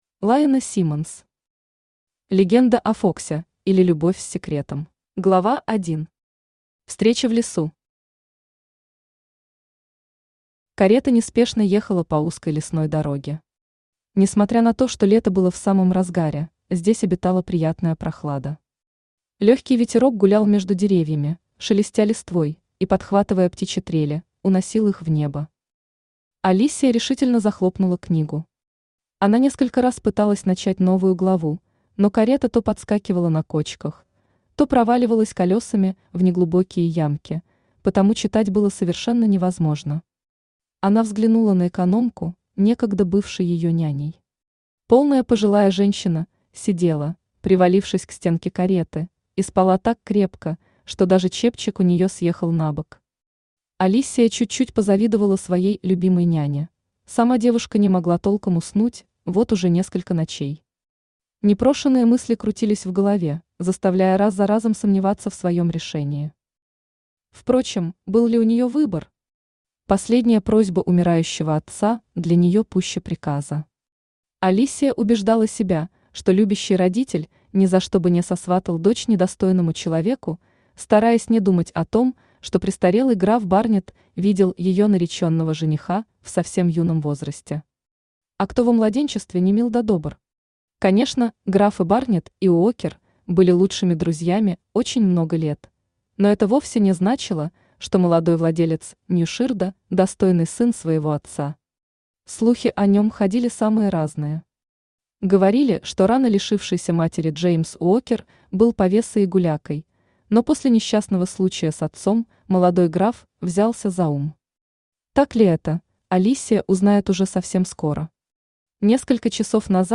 Аудиокнига Легенда о Фоксе, или Любовь с секретом | Библиотека аудиокниг
Aудиокнига Легенда о Фоксе, или Любовь с секретом Автор Lyana Seamens Читает аудиокнигу Авточтец ЛитРес.